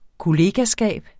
Udtale [ koˈleːgaˌsgæˀb ]